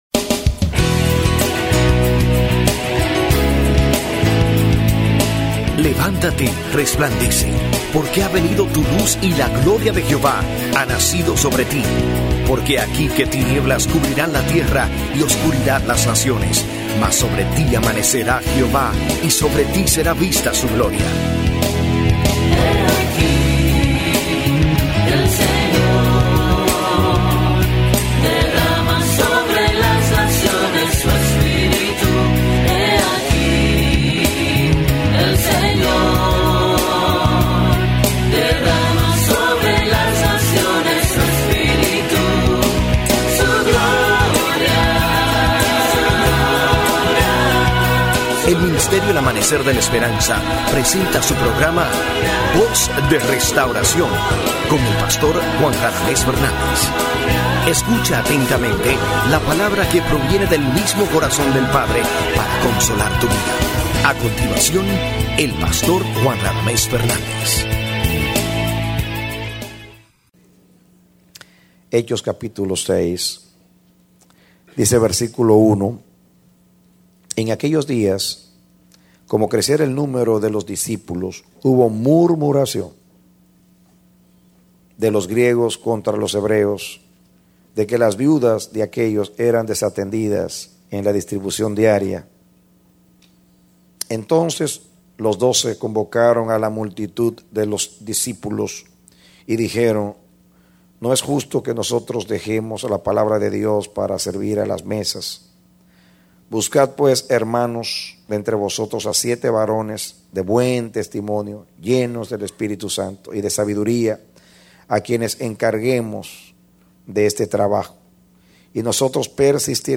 Mensaje: “La Iglesia Saludable # 6”